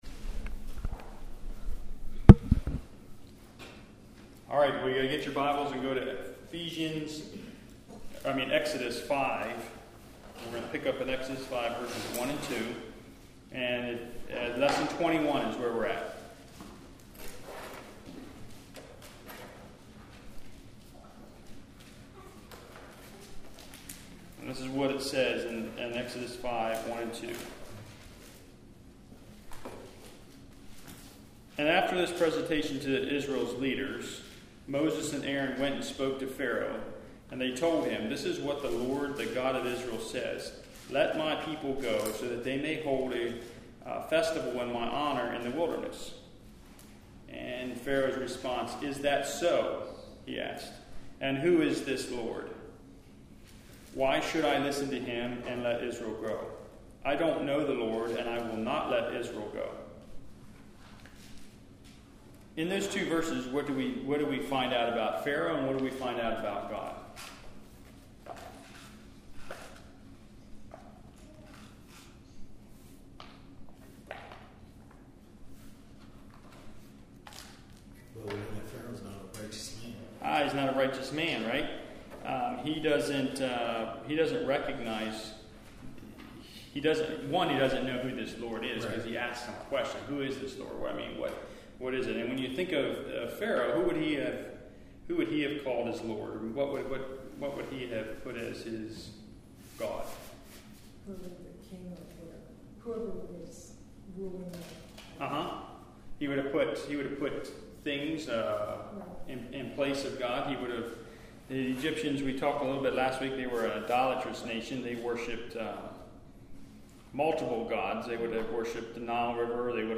Lesson 21